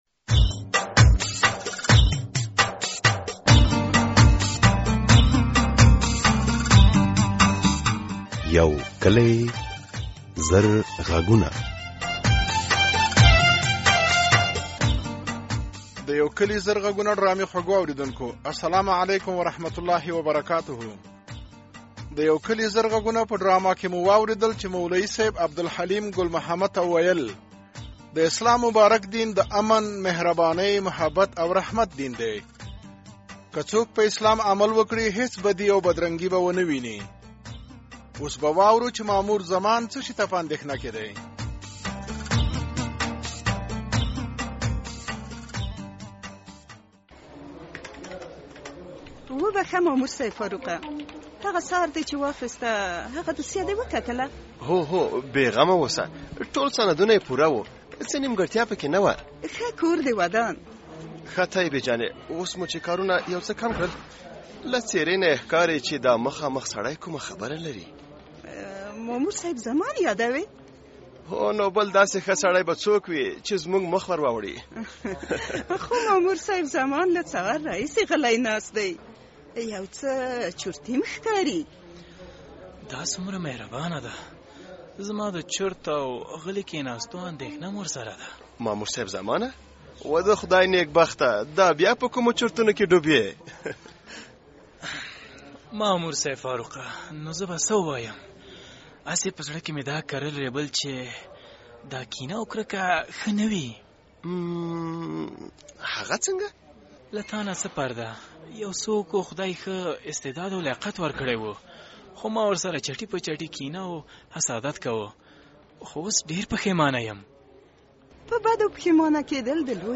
د یو کلي زر غږونو ډرامې په دې برخه کې له کوره بهر او په ...